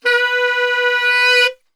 B 2 SAXSWL.wav